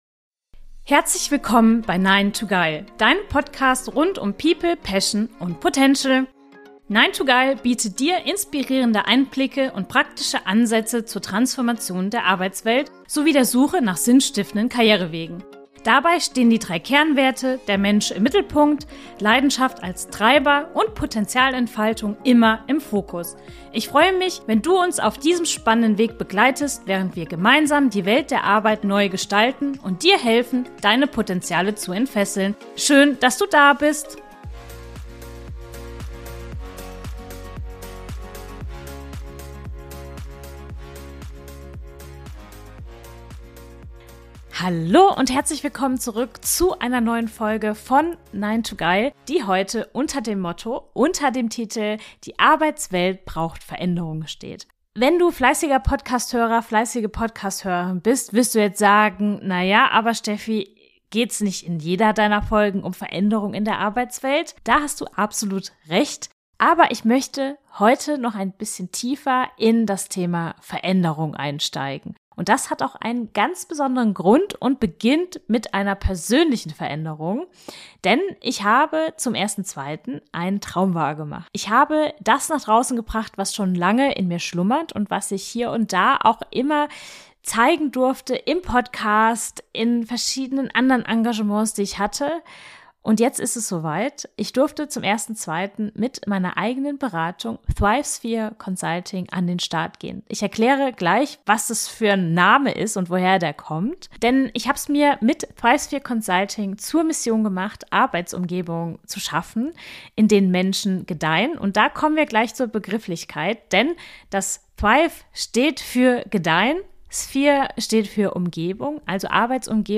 Nicht nur, weil ich seit langem mal wieder alleine am Mikrofon sitze, sondern auch weil es Neuigkeiten gibt.